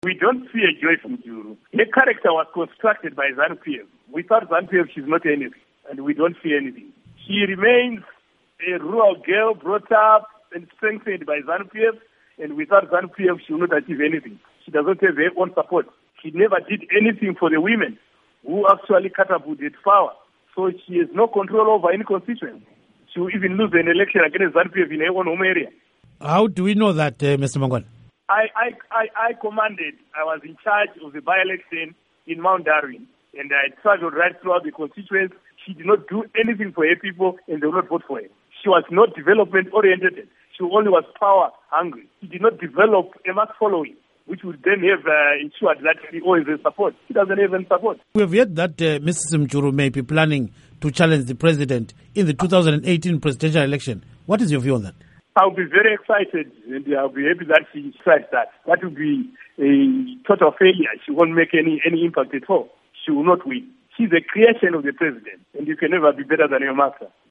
Interview With Munyaradzi Paul Mangwana on Joice Mujuru